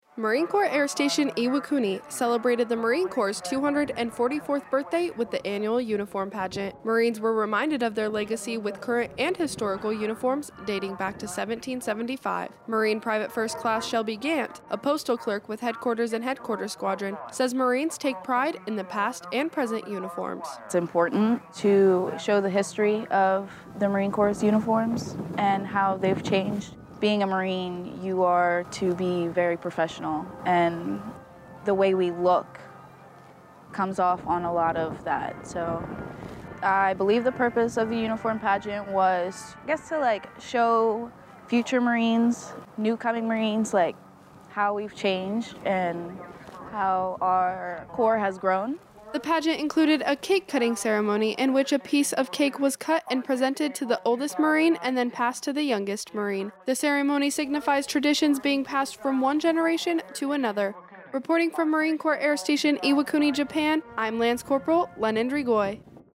MCAS Iwakuni celebrates 244th Marine Corps birthday (Radio)